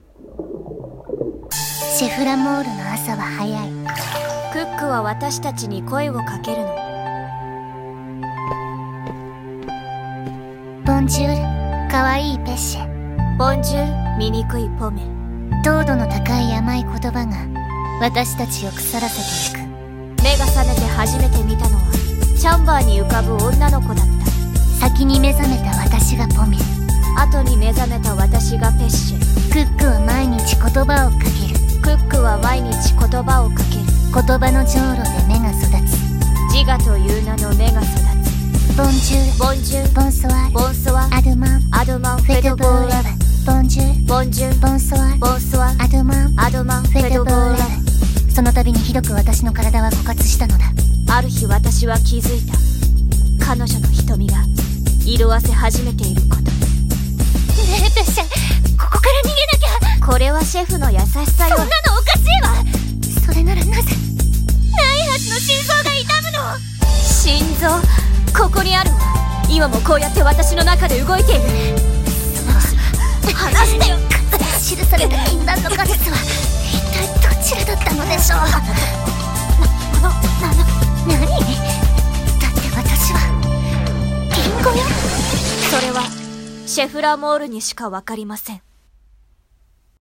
CM風声劇「ポミェとペッシェ」